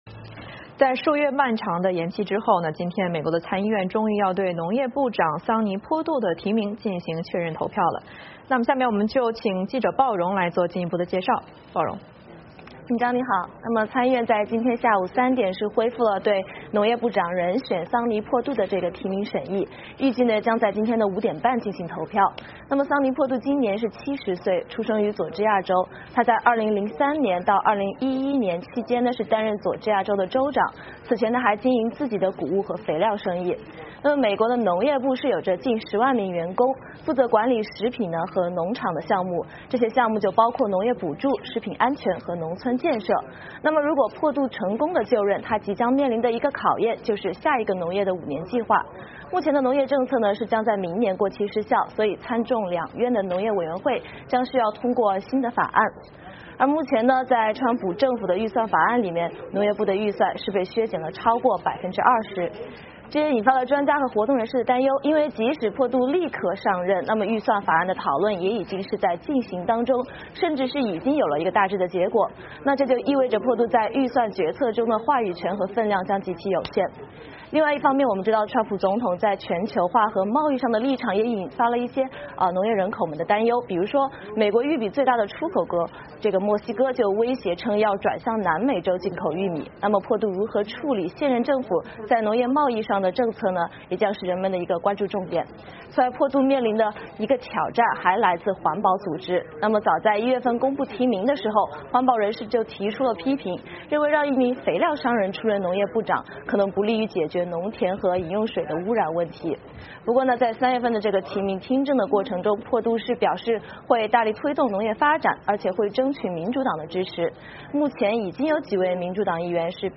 VOA连线：参议院预计通过农业部长桑尼·珀杜提名